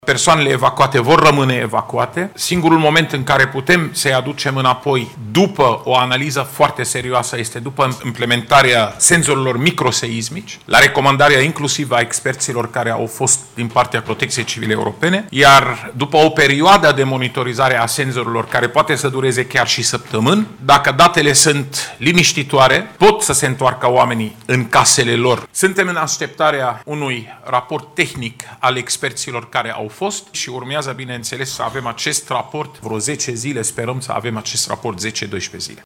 Șeful Departamentului pentru Situații de Urgență, Raed Arafat: „Suntem în așteptarea unui raport tehnic al experților”